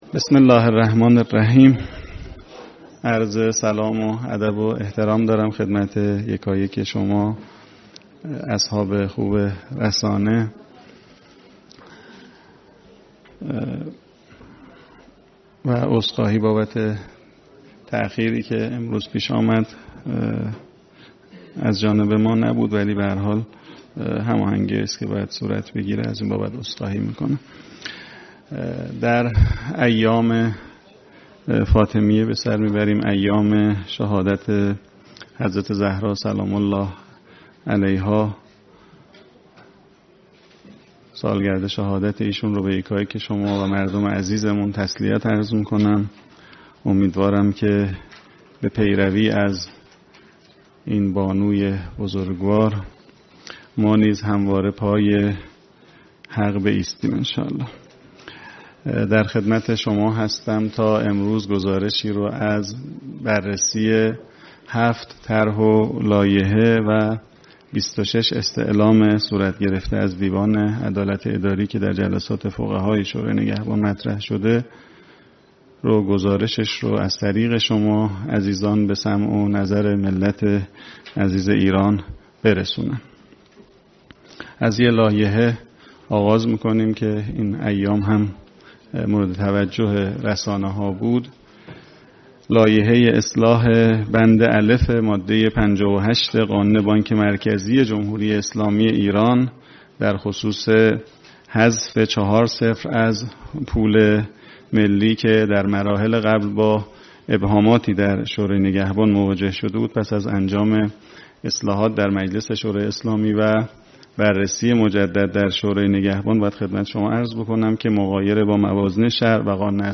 نشست خبری سخنگوی شورای نگهبان